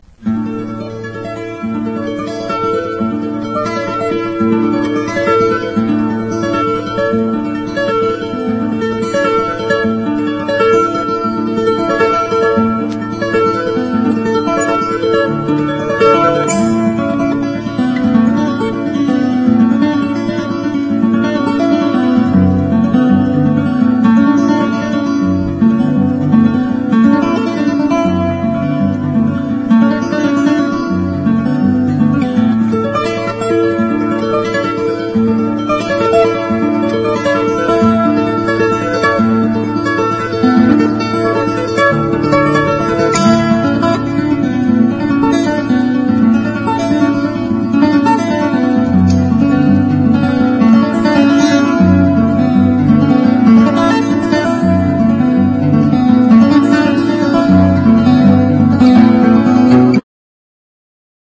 NEW AGE / ACOUSTIC
アコースティック・ギター・ニュー・エイジ！
ドラマチックで叙情的な世界観を繊細なニュアンスで表現しています。水彩画のように瑞々しいギターの音色。